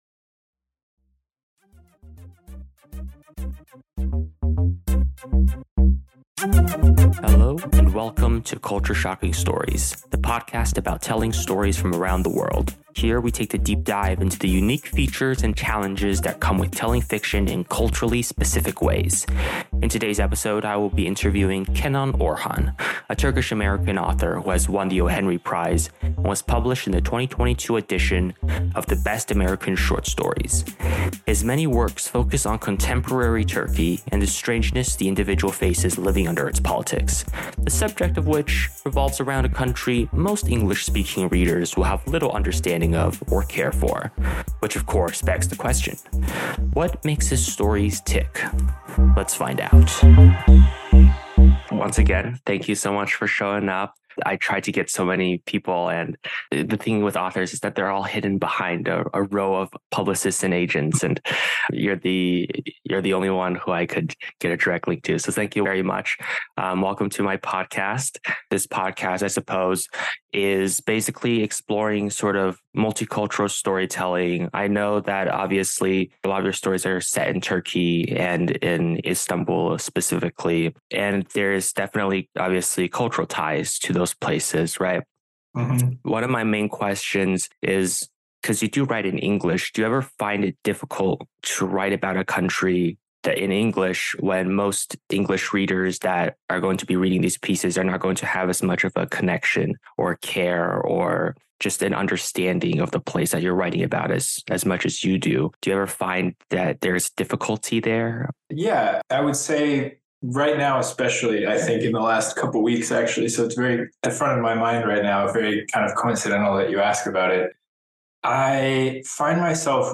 Interview Part 1